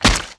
WAV · 17 KB · 單聲道 (1ch)